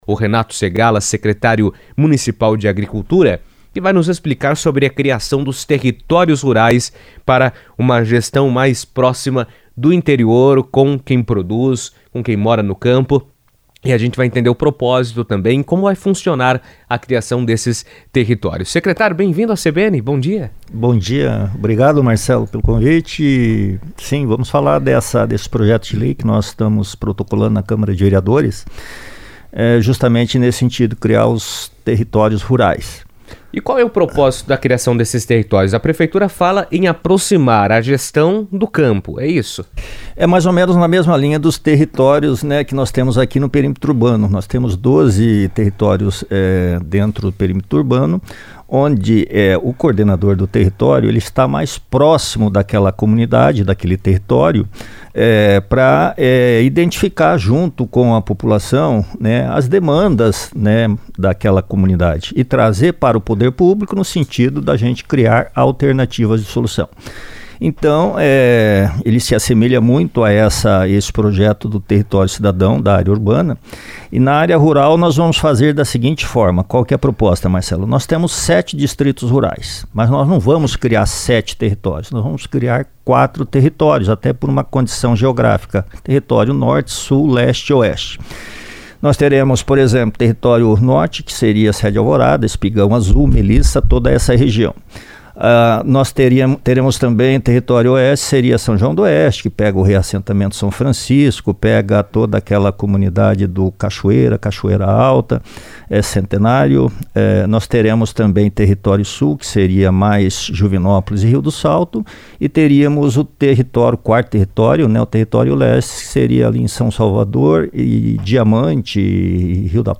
A iniciativa visa organizar melhor as regiões do interior, melhorar a infraestrutura e qualificar os serviços voltados à população rural. Em entrevista à CBN, Renato Segalla, secretário de Agricultura, destacou que a criação das coordenadorias permitirá diagnosticar e articular de forma mais eficiente as necessidades de cada território, tornando o planejamento regional mais ágil e alinhado às prioridades locais.